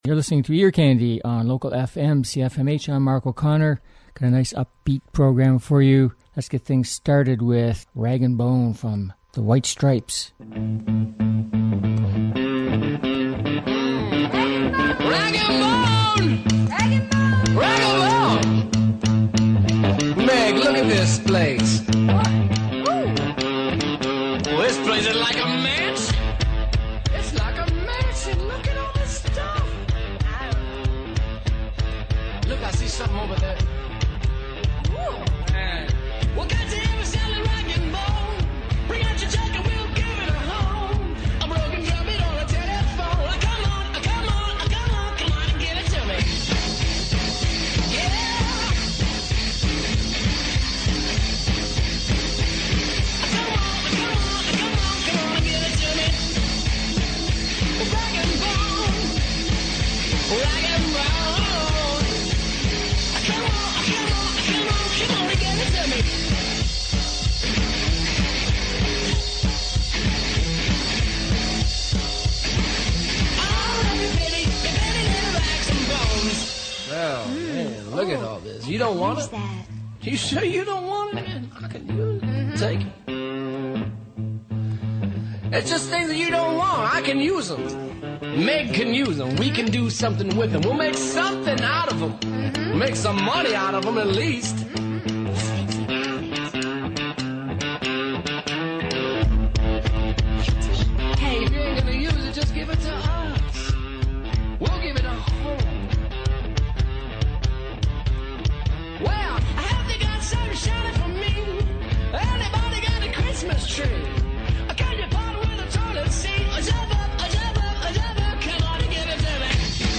Catchy Uptempo Pop/Rock Songs with lots of Cancon